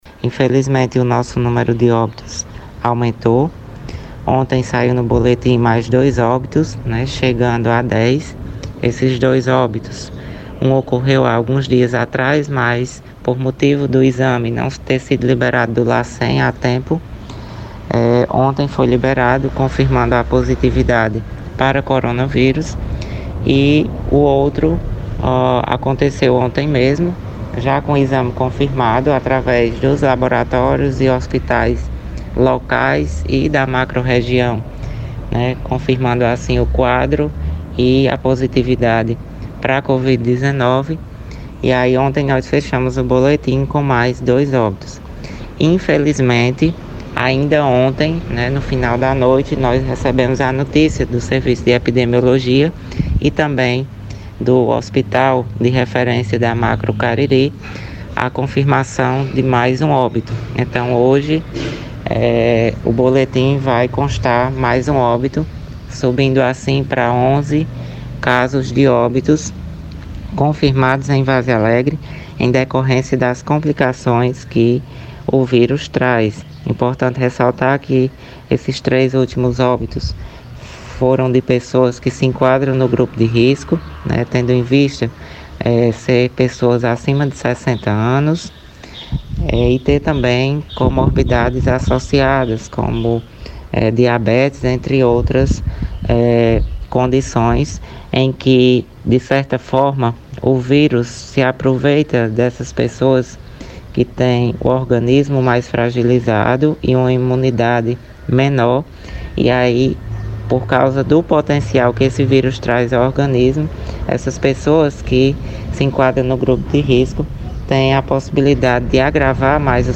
A cidade de Várzea Alegre tem mais uma morte por covid-19, doença do novo coronavírus. A informação é do secretário de saúde, Ivo Leal, à FM Cultura, na manhã deste sábado, 04, que trouxe mais detalhes dos óbitos anteriores.